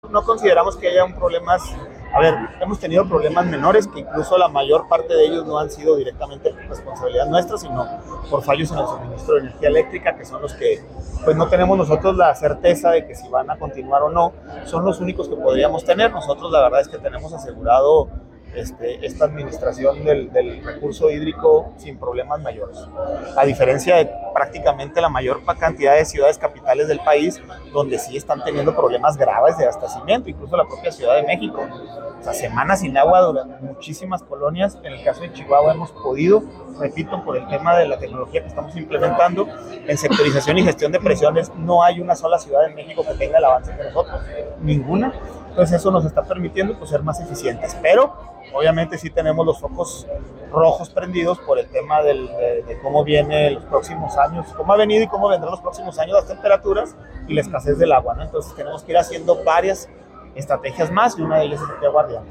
AUDIO: ALAN FALOMIR, DIRECTOR EJECUTIVO DE LA JUNTA MUNICIPAL DE AGUA Y SANEAMIENTO (JMAS) CHIHUAHUA